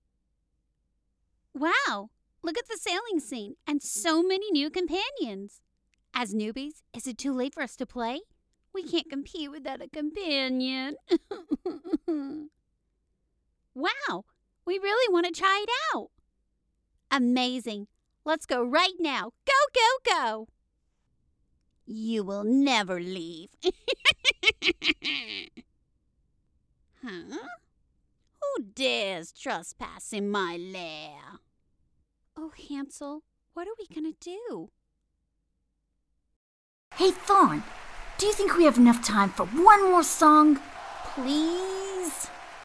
Video Games and Cartoons
British, Germain, Southern, French